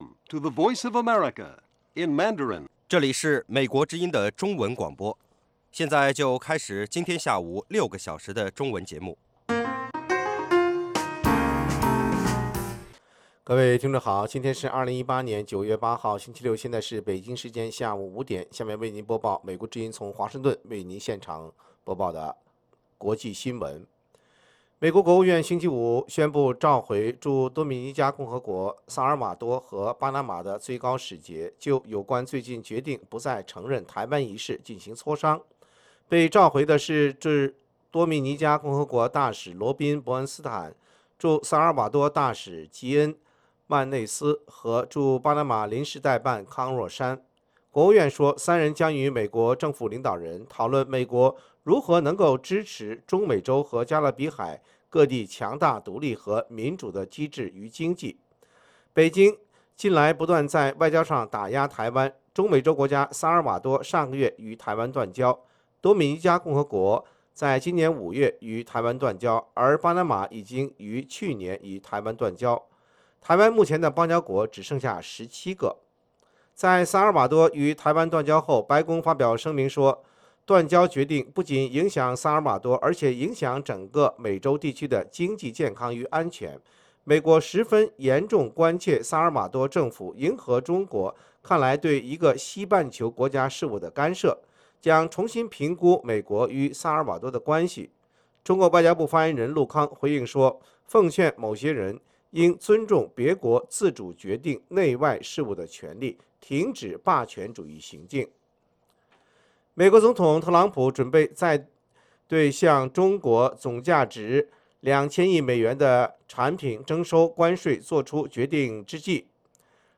北京时间下午5-6点广播节目。广播内容包括国际新闻，收听英语，以及《时事大家谈》(重播)